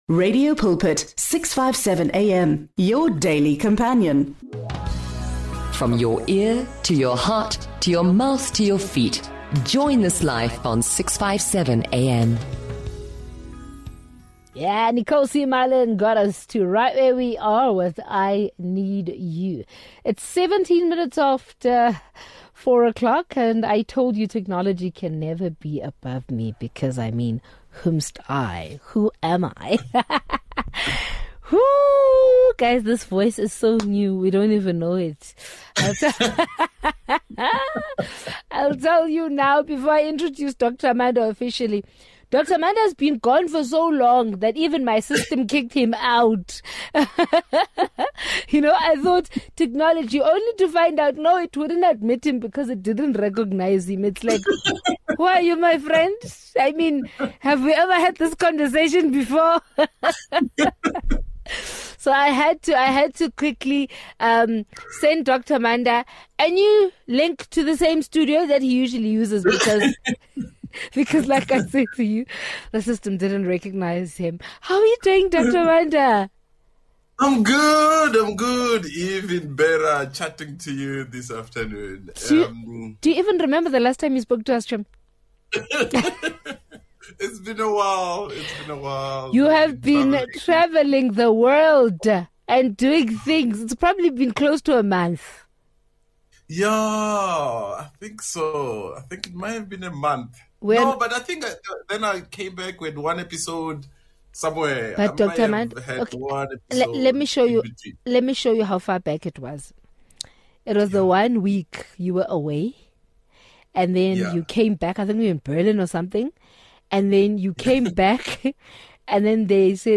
They emphasize the importance of creating time for reflection, resetting, and maintaining productivity. They also discuss how easily the sense of meaning can be lost and how leaders can support their teams in regaining and sustaining it. This conversation offers valuable insights into effective team leadership and motivation.